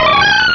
Cri de Feuforêve dans Pokémon Rubis et Saphir.